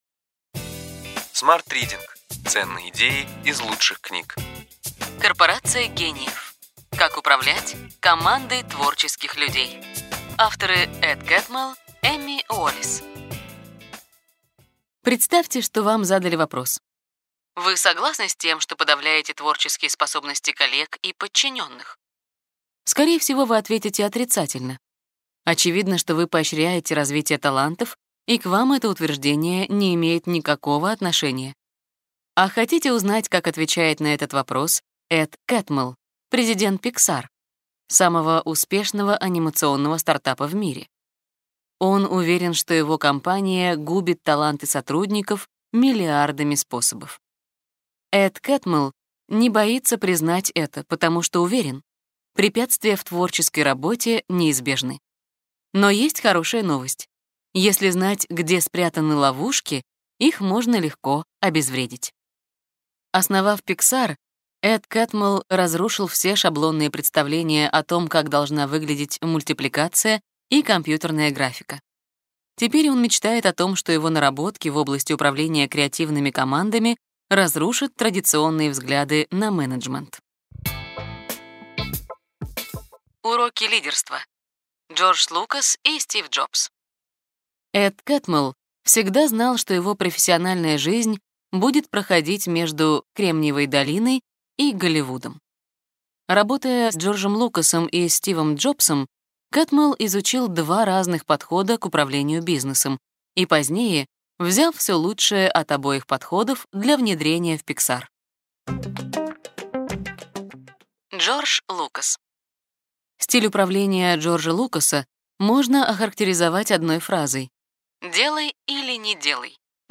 Аудиокнига Ключевые идеи книги: Корпорация гениев. Как управлять командой творческих людей. Эд Кэтмелл, Эми Уоллес | Библиотека аудиокниг